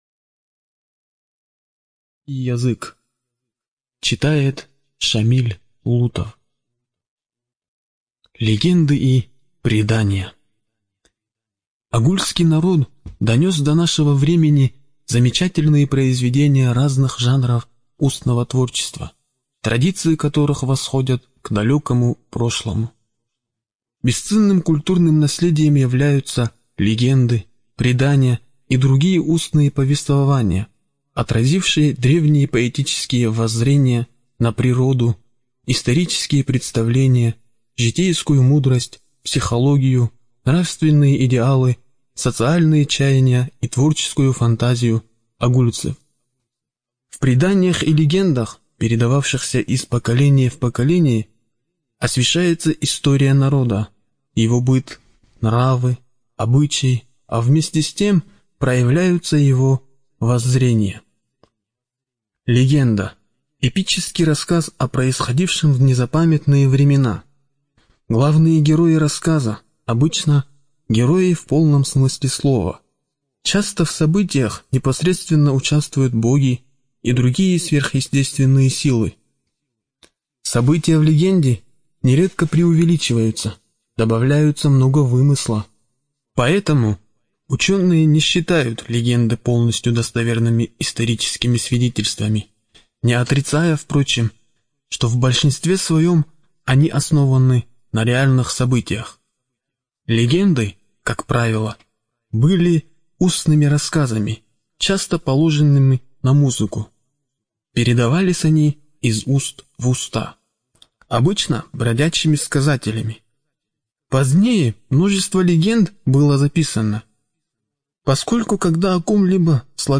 Студия звукозаписиДагестанская республиканская библиотека для слепых